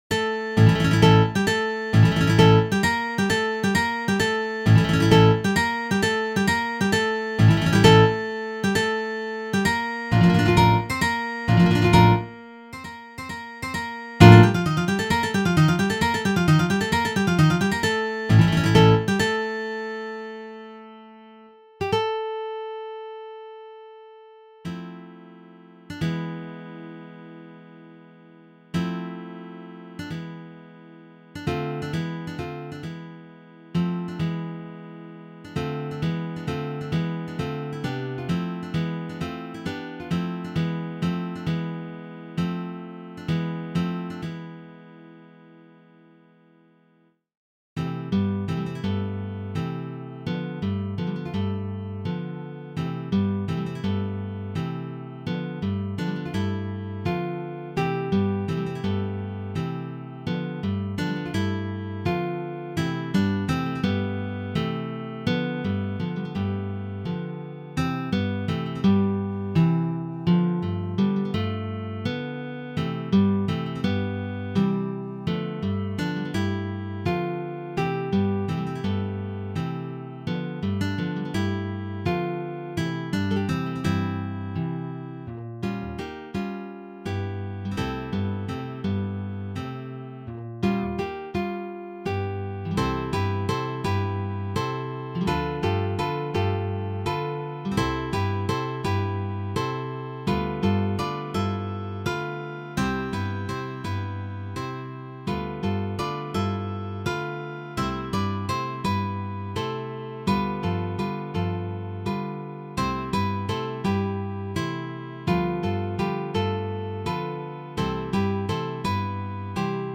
The two dances
Andalusian ballet